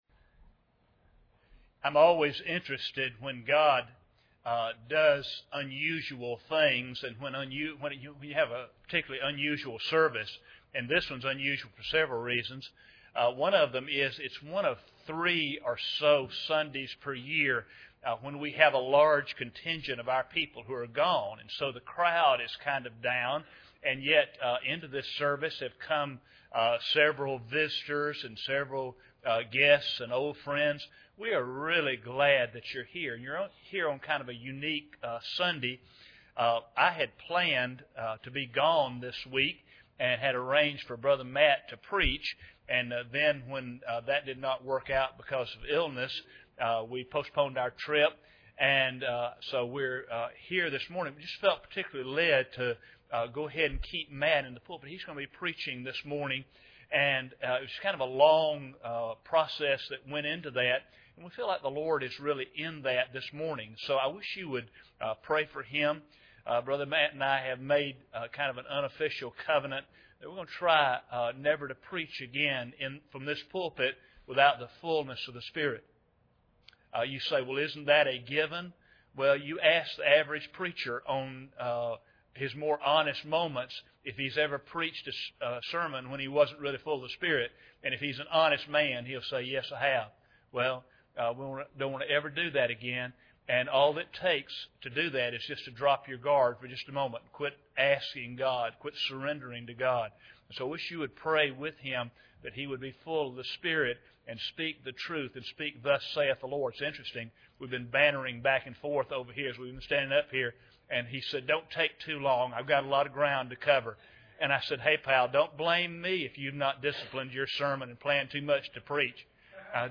Psalm 4:1-2 Service Type: Sunday Morning Bible Text
General A sermon on revival.